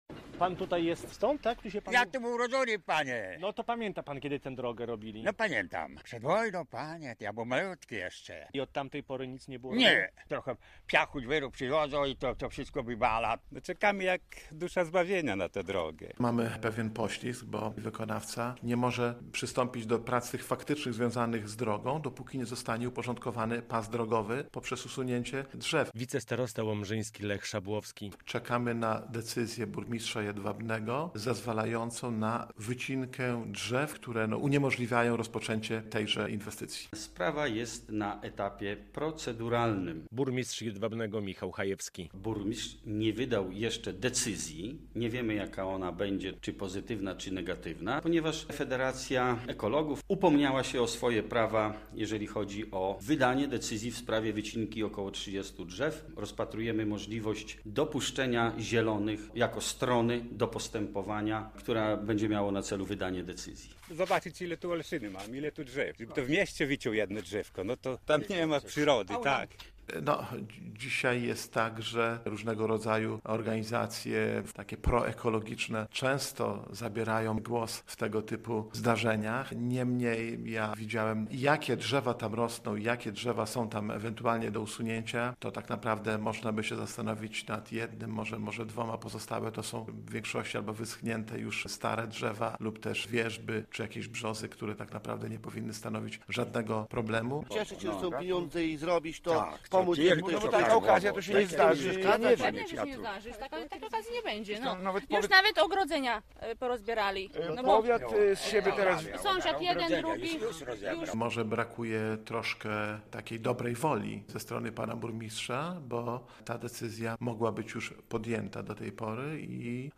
Brak decyzji w sprawie wycinki drzew w pasie drogowym od miesiąca blokuje inwestycję w gminie Jedwabne - relacja